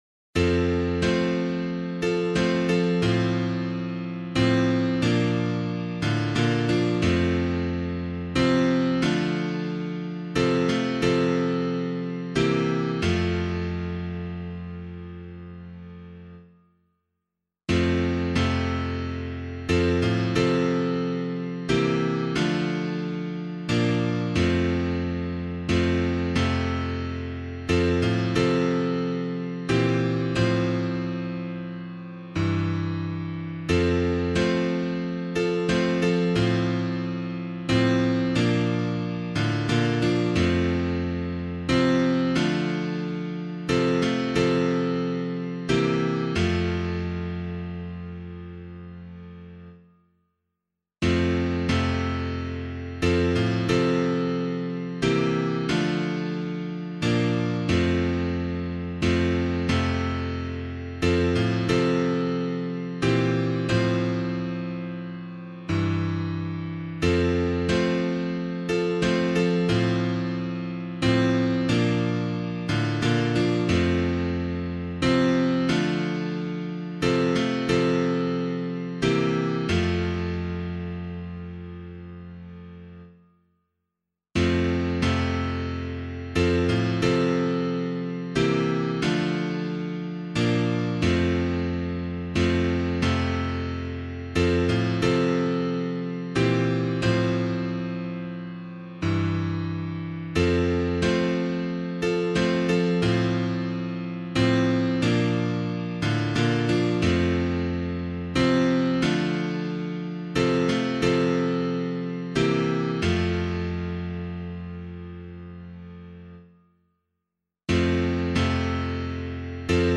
piano
The Thirsty Cry for Water Lord [Stuempfle Jr. - NEW BRITAIN] - piano.mp3